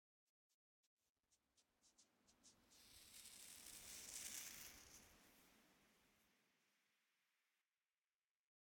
assets / minecraft / sounds / block / sand / sand9.ogg
sand9.ogg